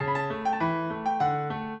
piano
minuet1-10.wav